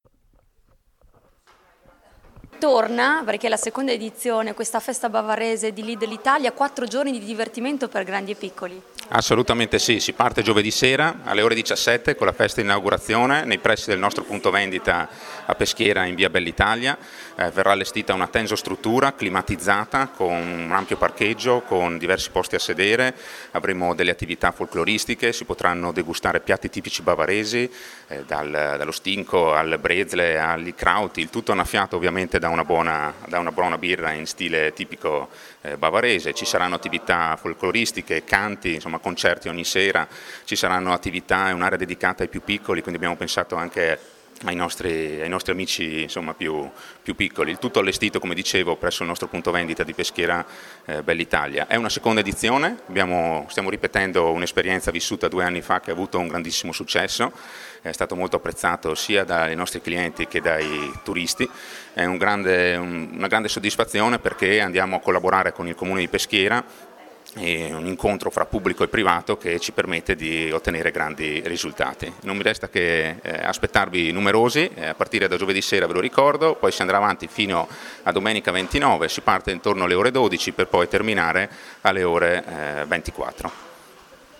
Di seguito le interviste ai protagonisti: